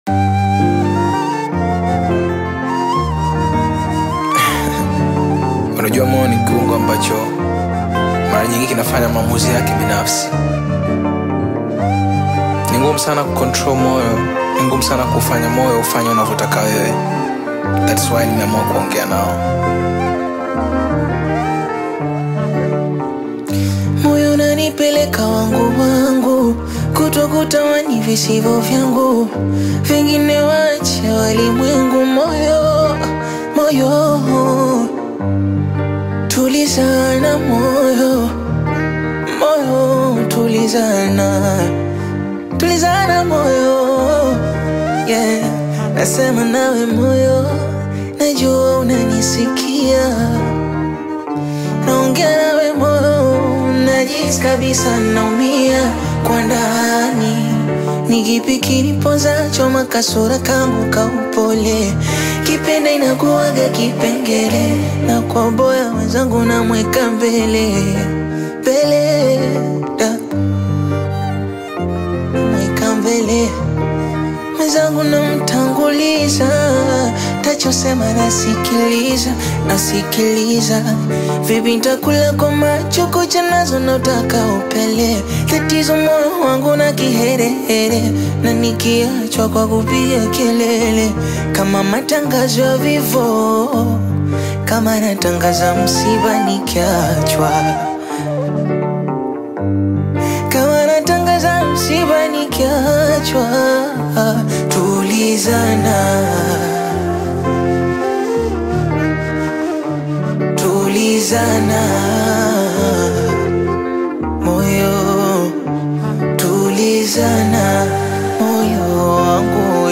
deeply emotional Bongo Flava/acoustic rendition
Genre: Bongo Flava